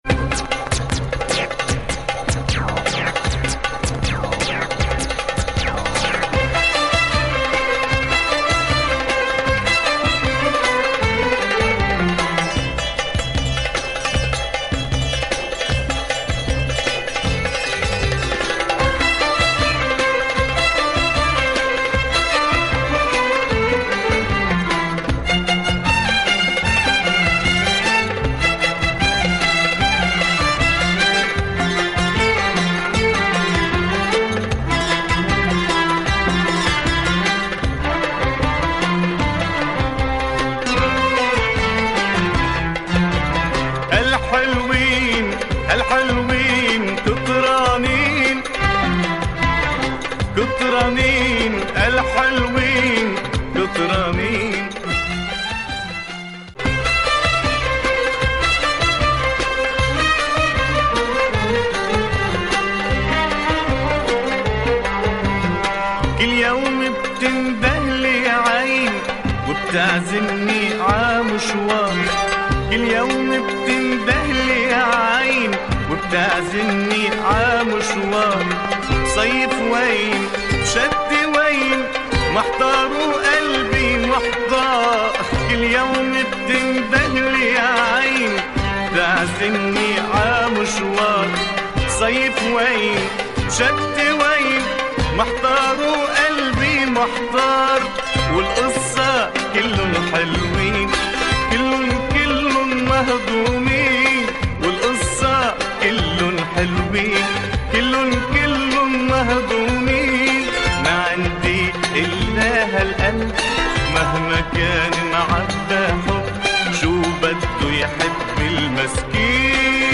Typical Lebanon sound
recorded in the middle of the civil war